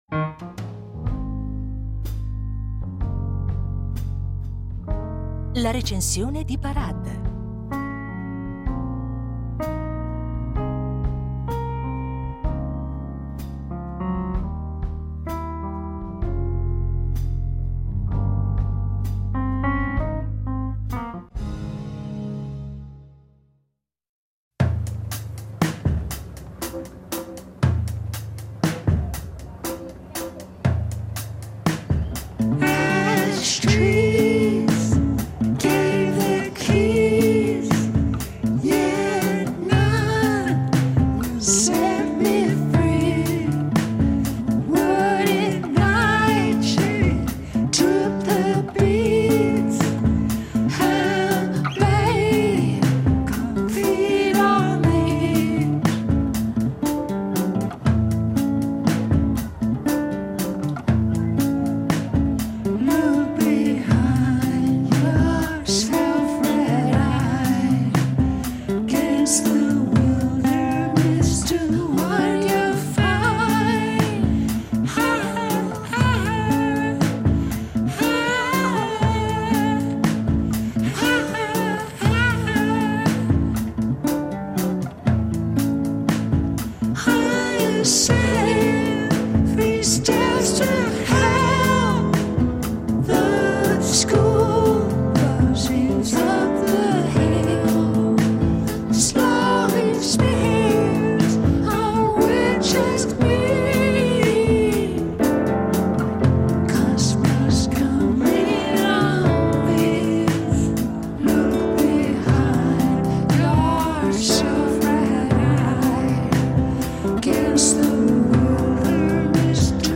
Nell’ultimo album tornano molti degli elementi che sono emersi nelle produzioni precedenti, con un filo conduttore costituito da un’esplorazione del registro vocale più acuto, al limite e anche oltre il limite dell’estensione “naturale” della cantante.